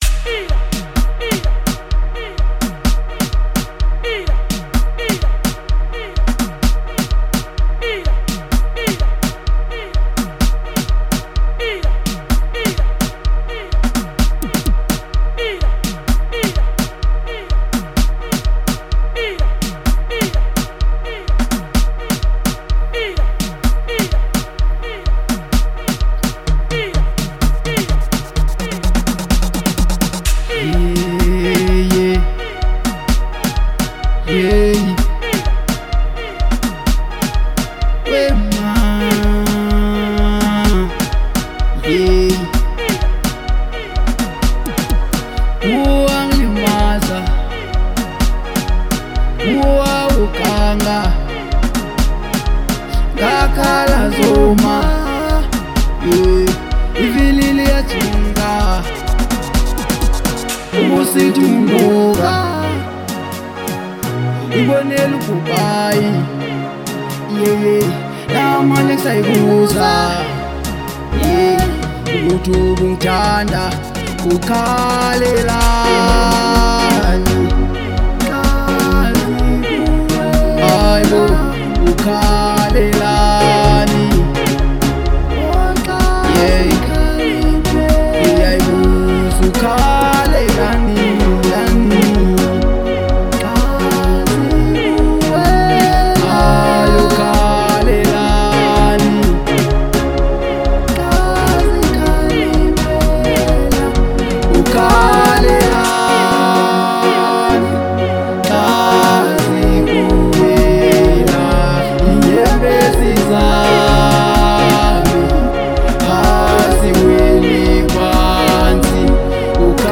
04:51 Genre : Gqom Size